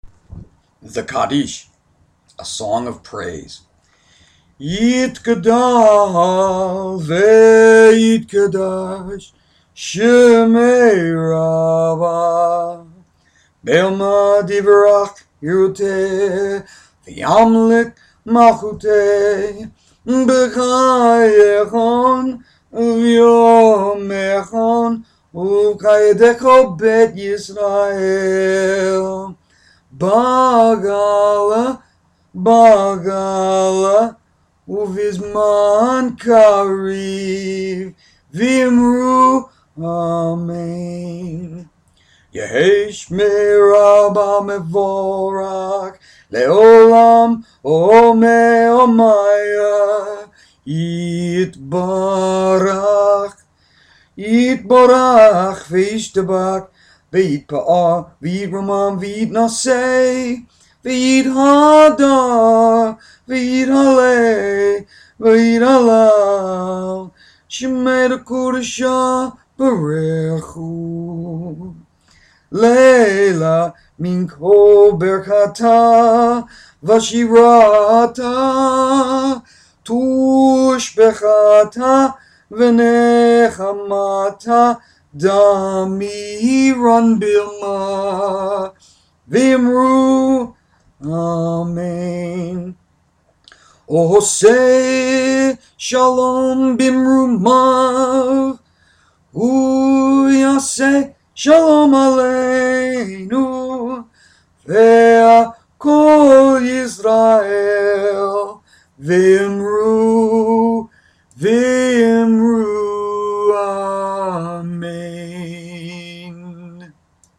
Hebrew Chants
Now ... if you can tolerate the poor voice one should at least be able get a feel for the basic rhythm and cadence of some of the well known Hebraic prayer chants.